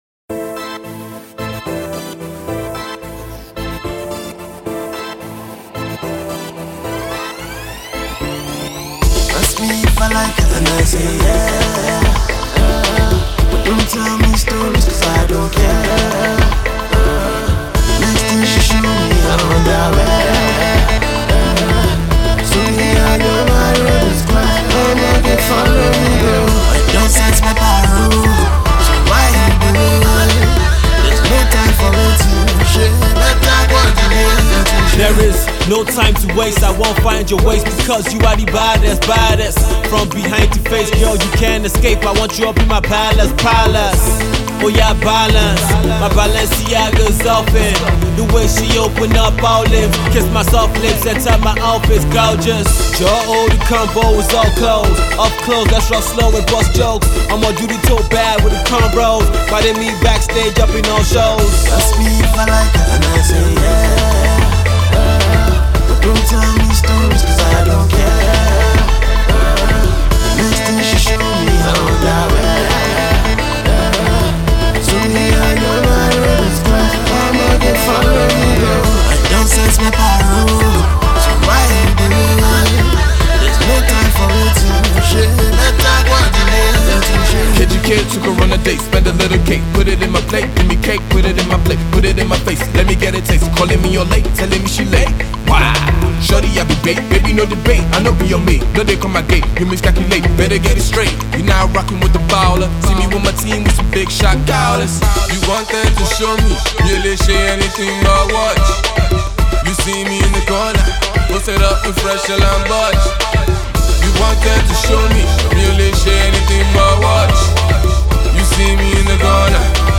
simple verses